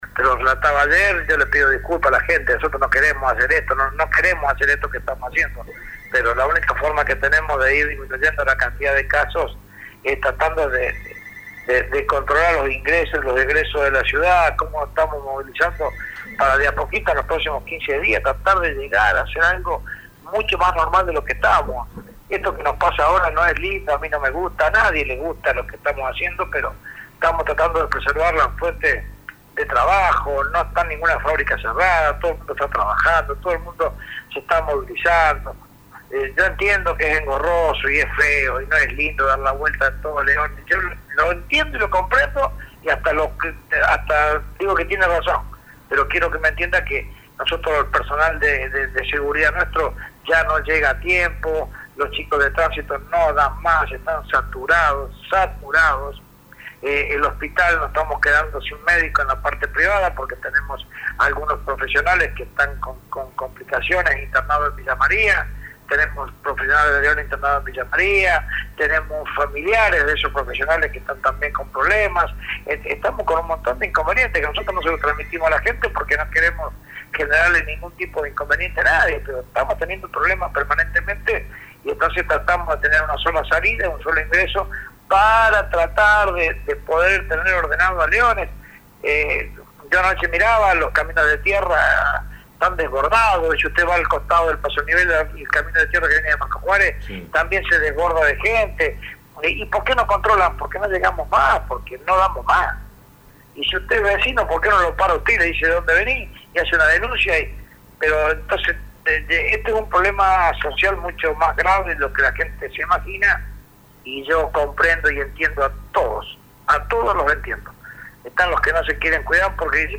El intendente habló de la situación en Leones.
Fabián Francioni habló con La Mañana sobre la situación sanitaria en Leones, los controles que rigen desde el lunes para ingresos y egresos a la localidad, y el comportamiento de un sector de la ciudadanía que descree del «virus».